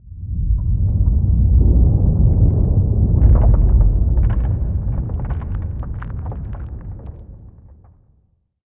cave3.ogg